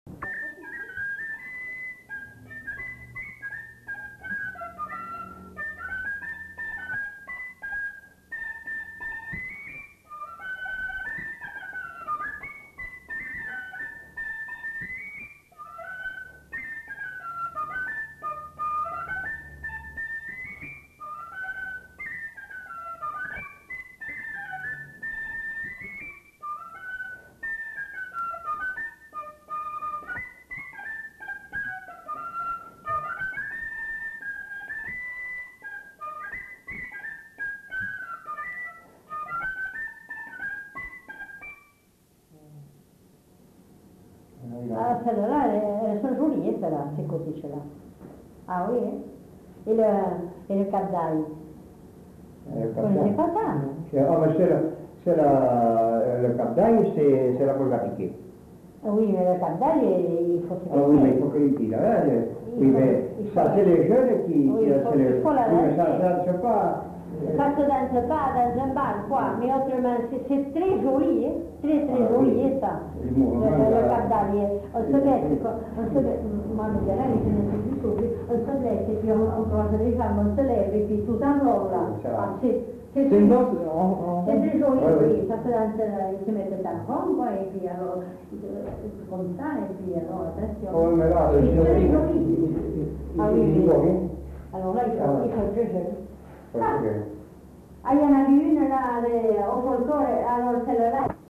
Répertoire d'airs du Bazadais interprété au fifre
enquêtes sonores